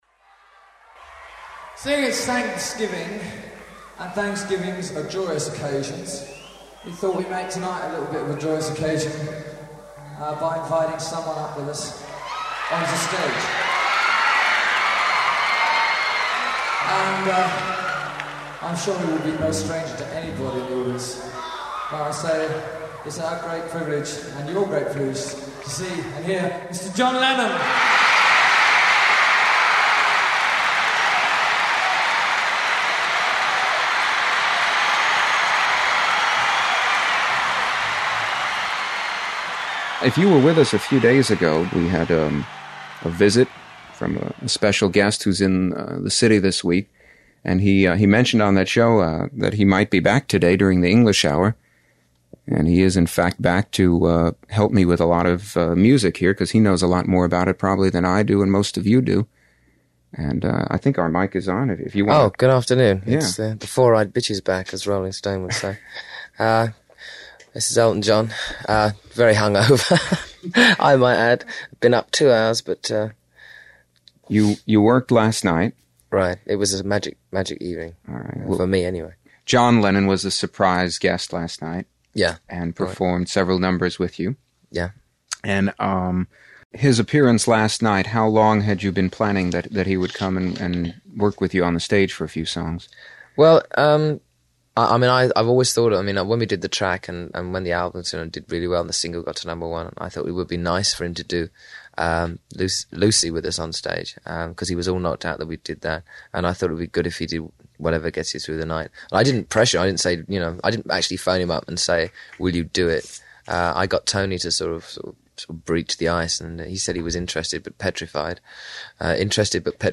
Interview
at WNEW-FM on  November 29, 1974, the day after the Thanksgiving Madison Square Garden concert.